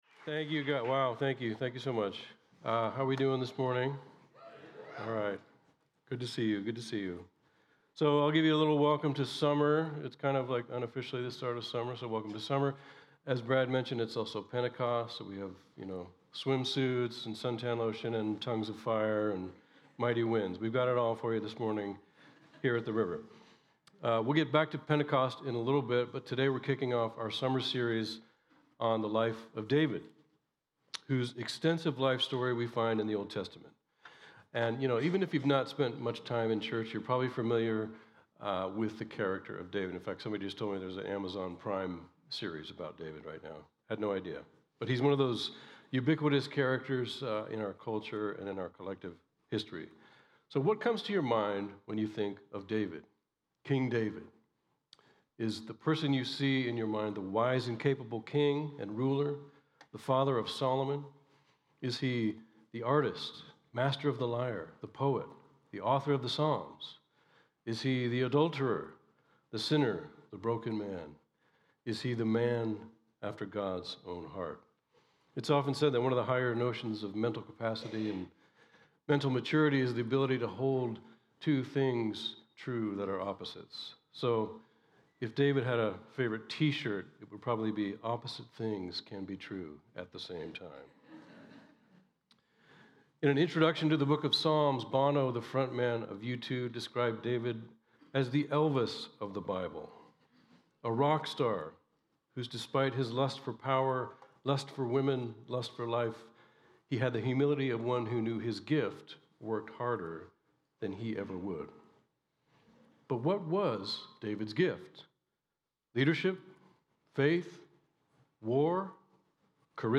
The River Church Community Sermons David: Man of Valor - Calling